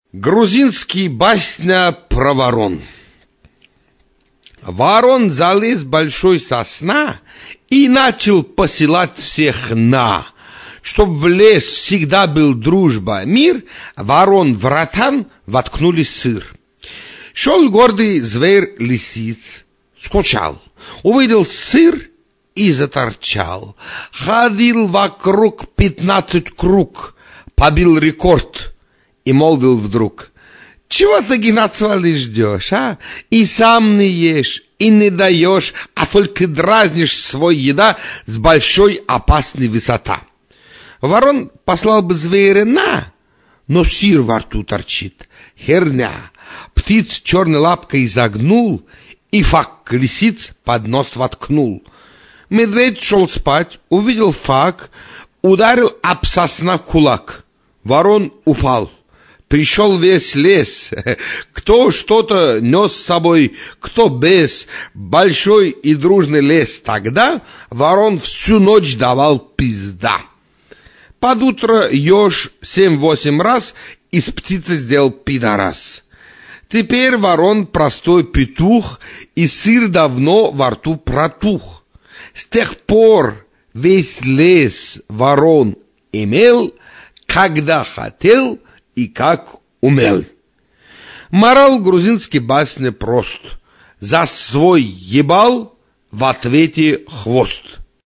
Basnya.mp3